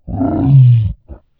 Add dino roar sound files
dino-roar-02.wav